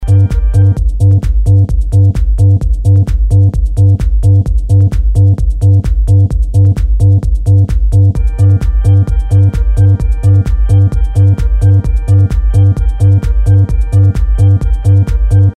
ist eine oktavierte gitarre). die fläche ist übrigens auch eine gitarre und im hintergrund ist noch ein "sinus-bass" auf der bd enthalten.